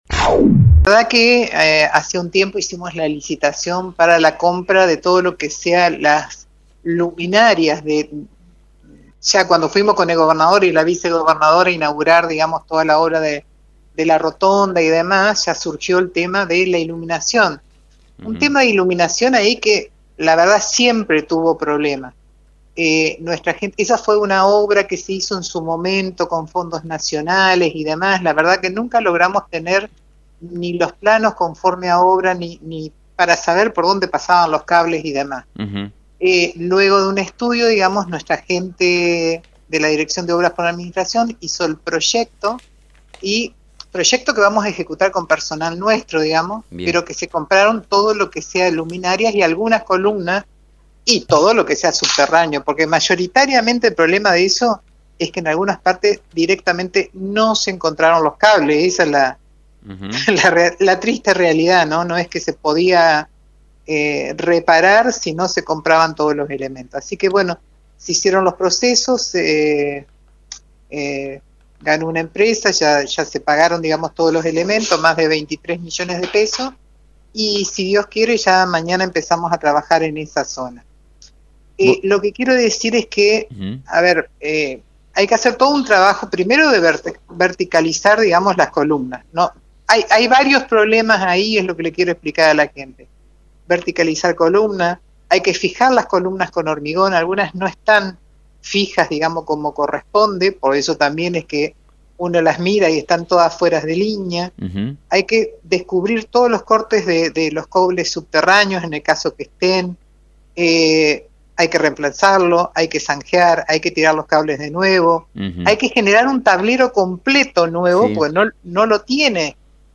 Alicia Benítez, administradora de Vialidad Provincial, dialogó con FM 90.3 sobre los trabajos que se van a realizar en uno de los ingresos a la ciudad de Victoria.